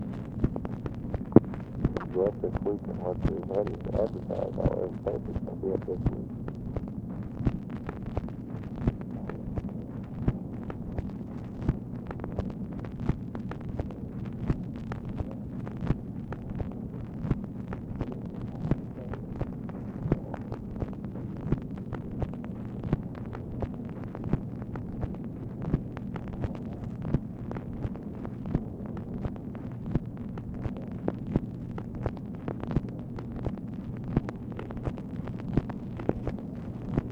OFFICE CONVERSATION, February 25, 1964
Secret White House Tapes | Lyndon B. Johnson Presidency